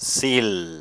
Tryck HÄR för att höra mig säga ordet...